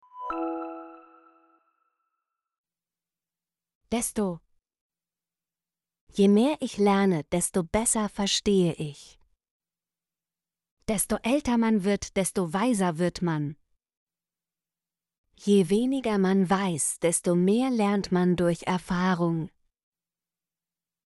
desto - Example Sentences & Pronunciation, German Frequency List